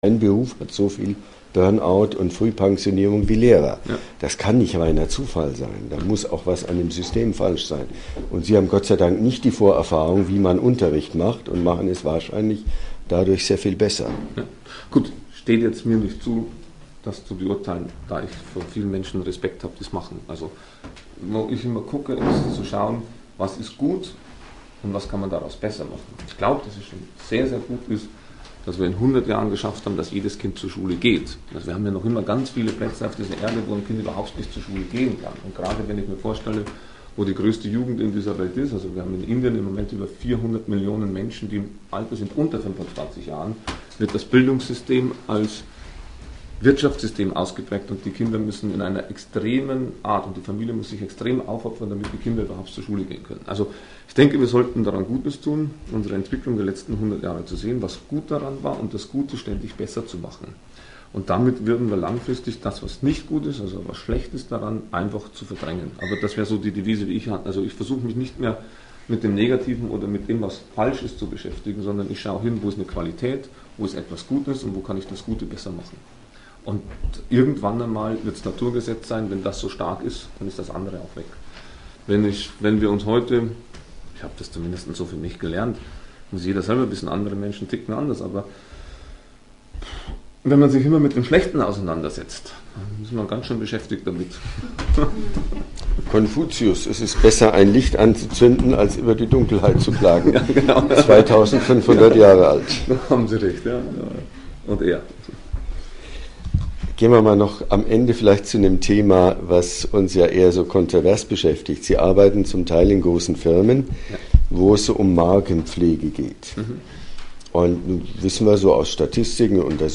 Labor-Interview